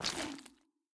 Sculk_step6.ogg.ogg